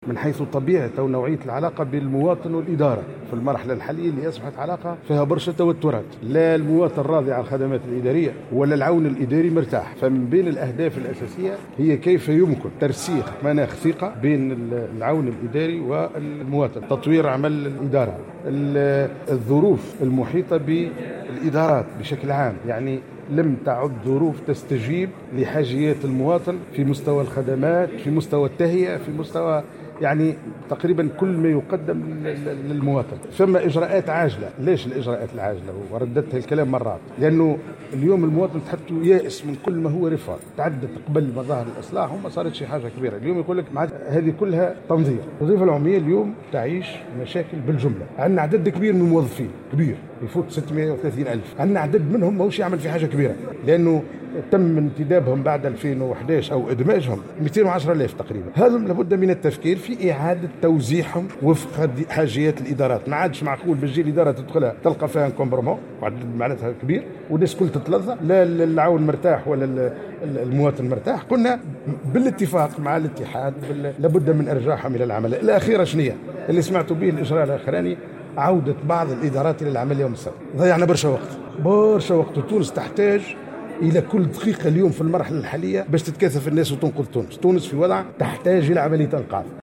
وأكد أن إعادة توزيع الموظفين بين الإدارات يهدف كذلك إلى تحقيق مردودية أفضل للإدارة، وإلى توفير خدمات يحظى بها المواطن في أحسن الظروف وبمستوى الجودة المطلوبة، وذلك خلال إشرافه اليوم الخميس في جرجيس على افتتاح أشغال الندوة الإقليمية لولايات صفاقس وقابس ومدنين وتطاوين حول مشروع الاستراتيجية الوطنية لتحديث الادارة والوظيفة العمومية.